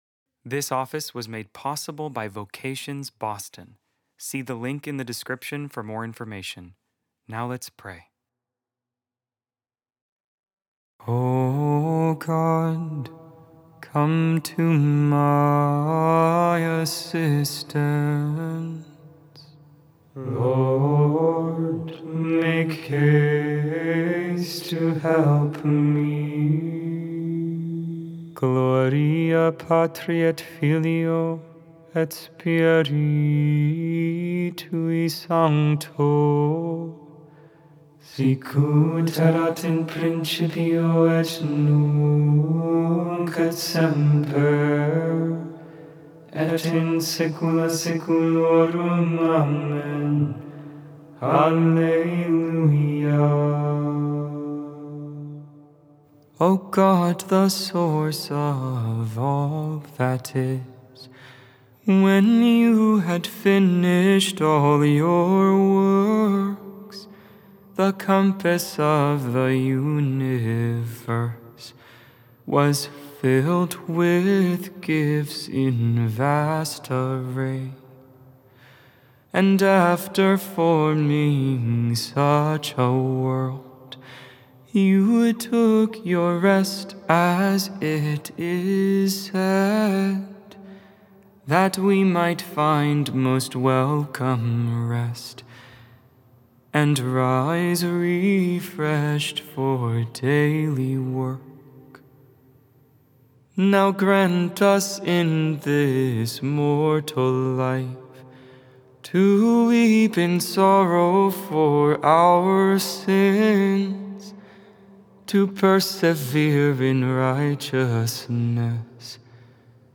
Made without AI. 100% human vocals, 100% real prayer.